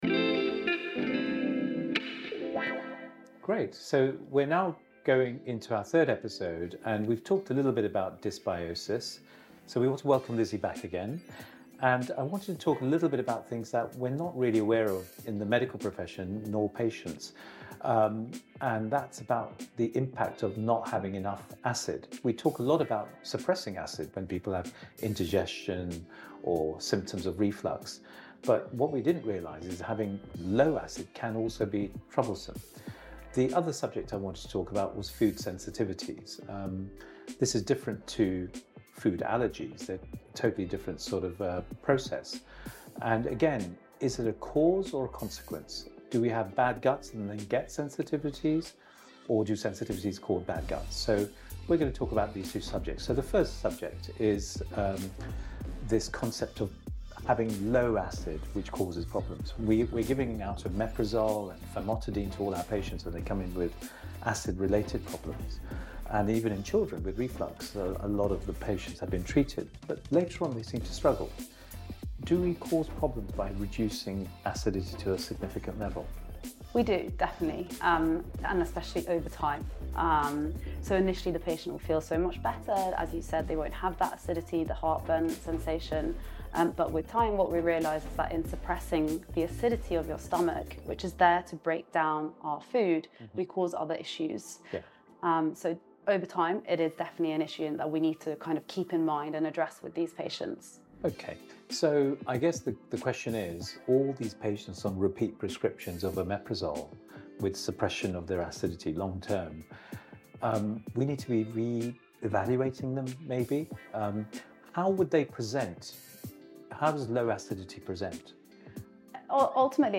This episode follows a discussion about the consequences of suppressed …